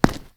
concrete_step_2_-04.wav